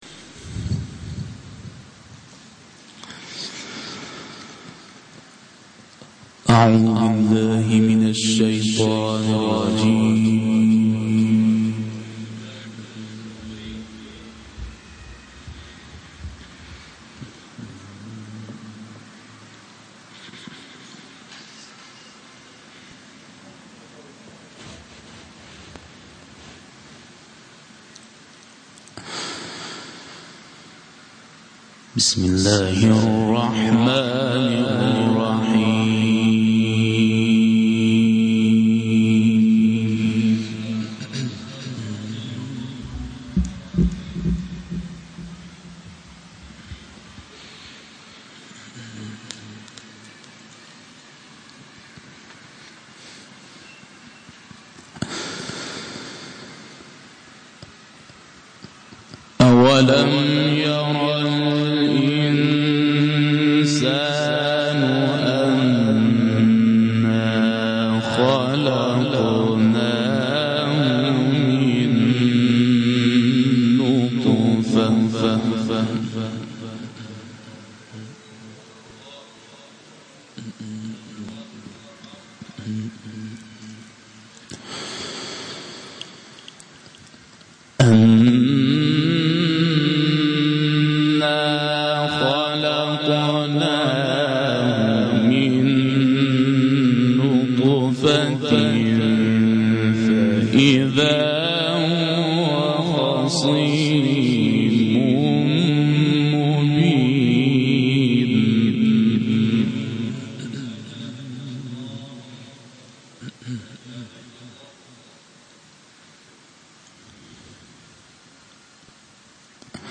گروه فعالیت‌های قرآنی - صوت تلاوت